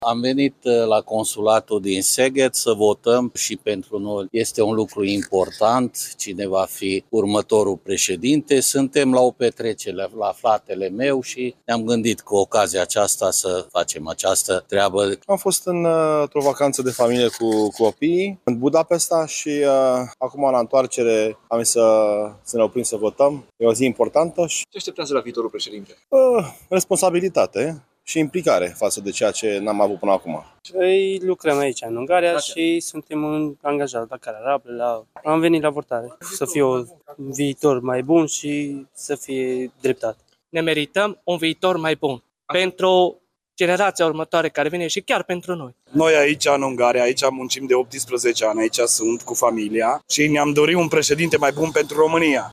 La secția de votare de la consulatul României din Szeged, Ungaria, votează români care sunt în vacanță, dar și maghiari cu dublă cetățenie. Vor mai multă responsabilitate de la viitorul președinte, dar și mai multă implicare.